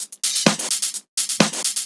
Index of /VEE/VEE Electro Loops 128 BPM
VEE Electro Loop 394.wav